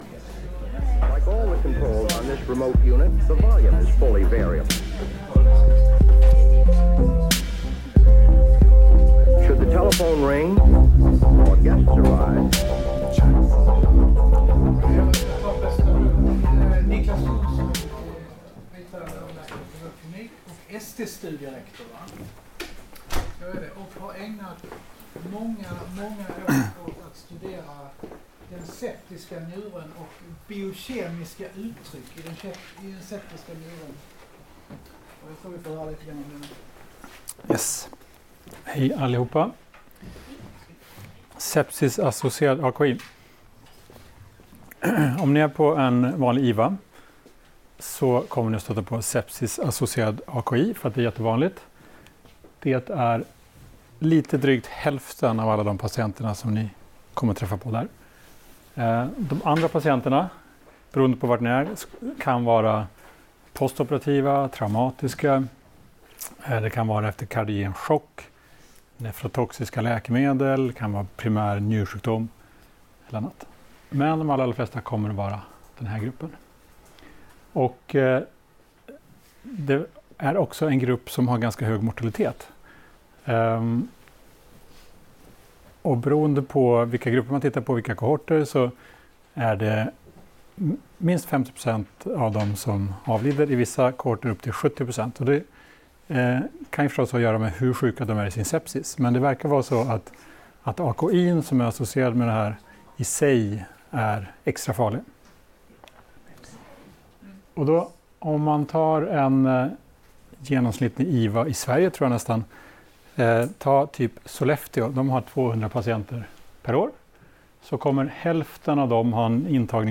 Inspelningen gjordes under Dialyskursen på Karolinska i Solna, 21 mars 2019.